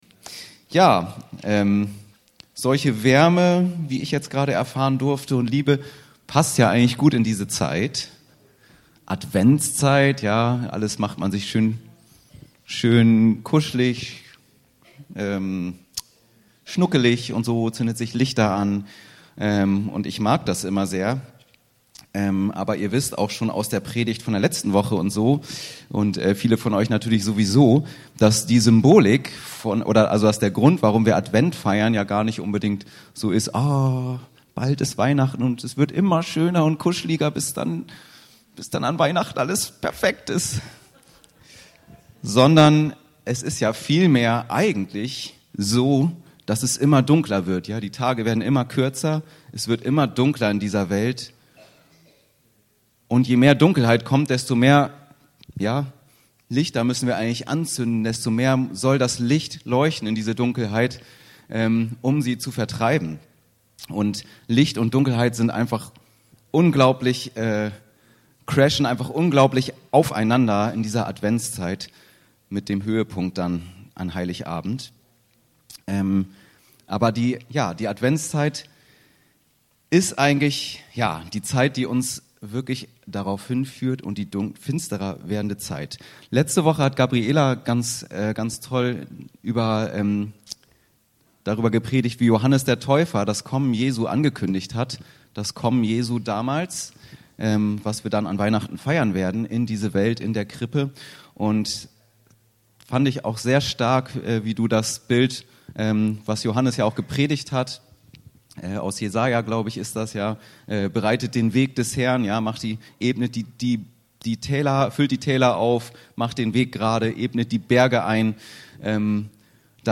Wie kommt Jesus wieder? Umgang mit der Endzeiterwartung. Luk 21,5ff ~ Anskar-Kirche Hamburg- Predigten Podcast